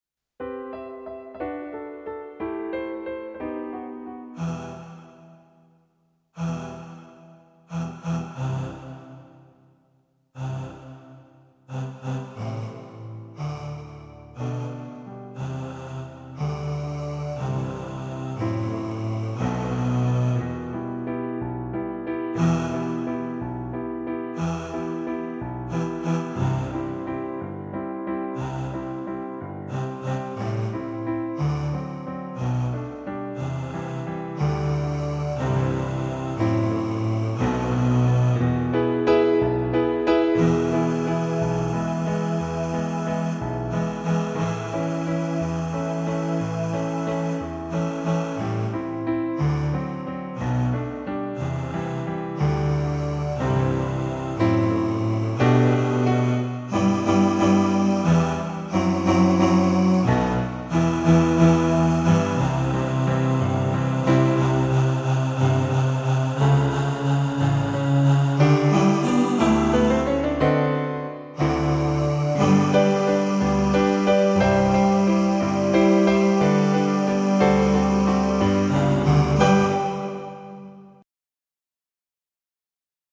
My-Favourite-Things-Bass.mp3